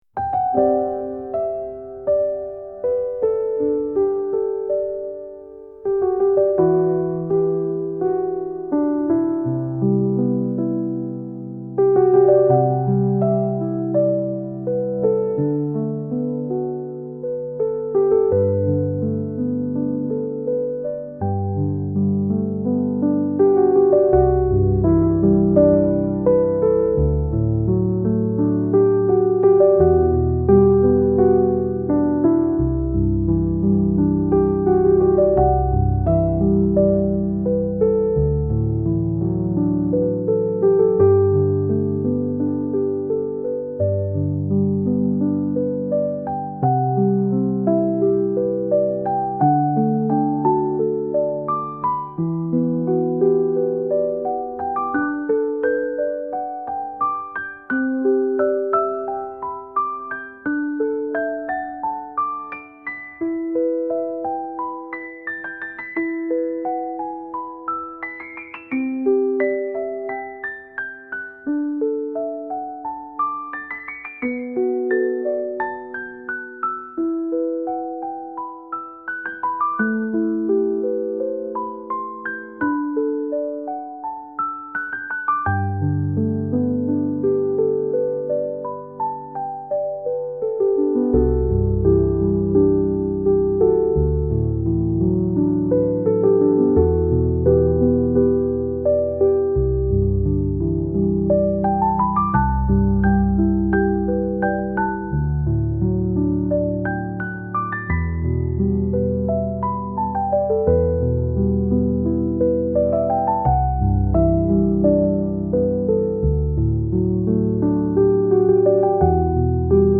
Genres: Background